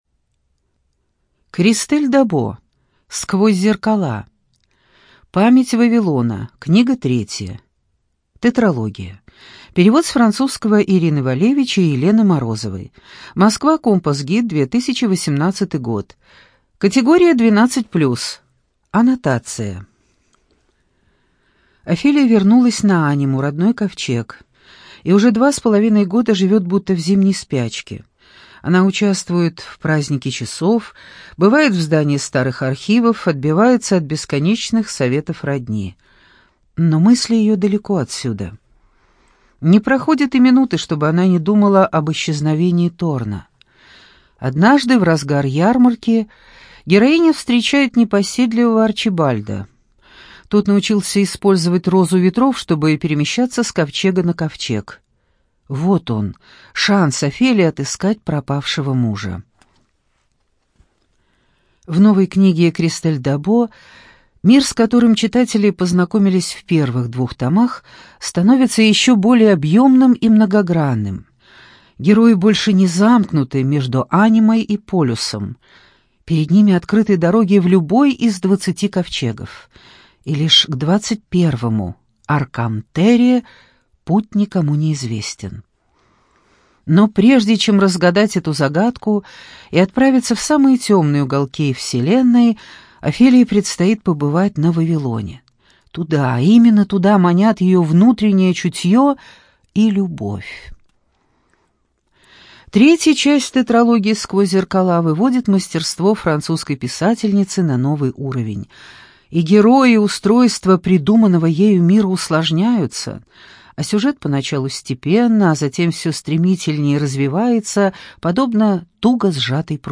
ЖанрФэнтези
Студия звукозаписиЛогосвос